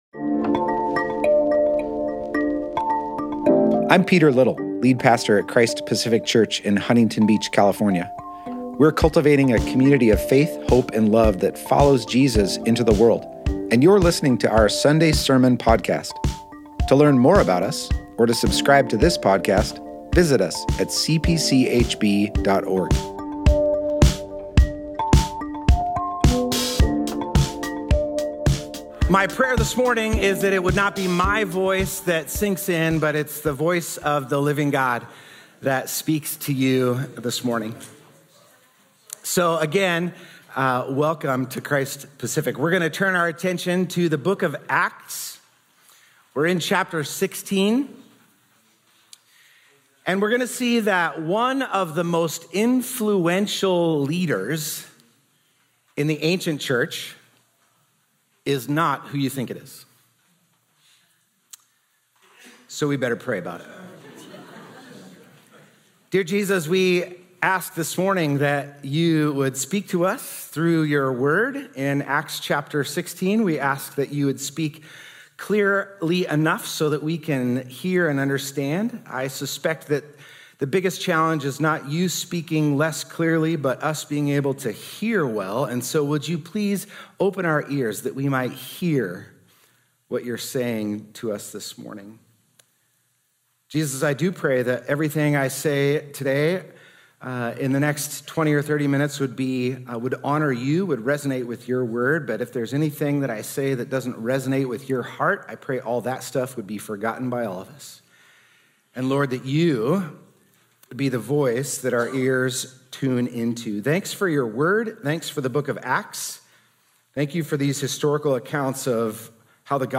Thank you for joining us this morning we will continue in our current sermon series, Advance.